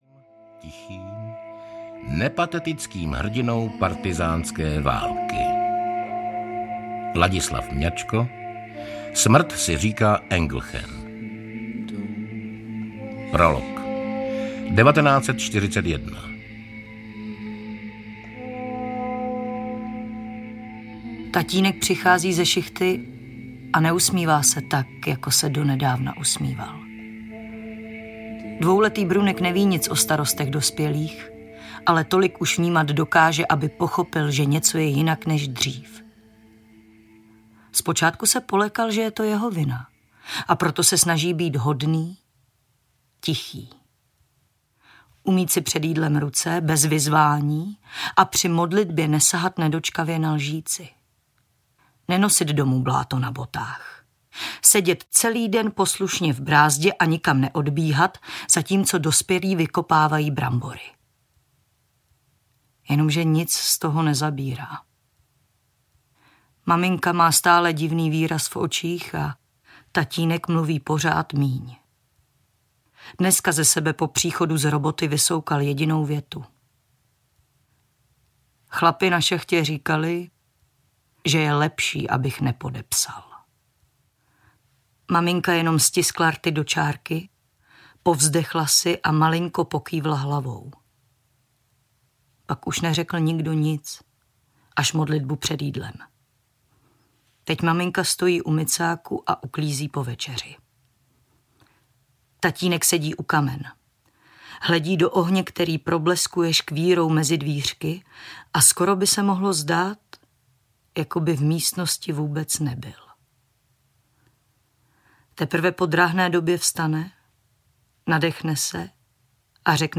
Životice audiokniha
Ukázka z knihy
• InterpretZuzana Truplová, Norbert Lichý